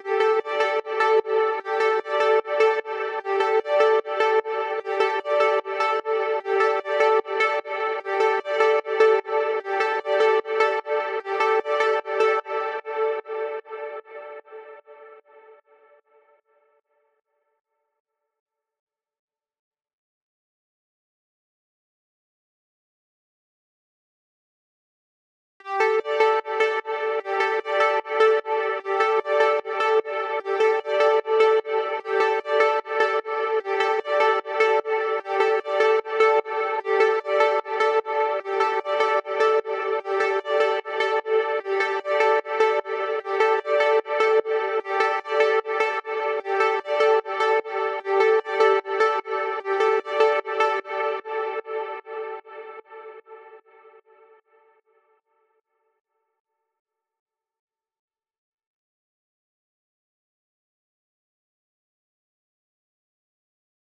🔹 52 Premium Serum Presets built for synthwave, retro pop, and nostalgic melodic house.
Lush Keys & Warm Pads – Layered textures that glide through the mix